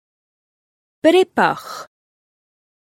Amazon AWS (pronunciation: breabach [NB: Unfortunately I haven't found a link to the noun]).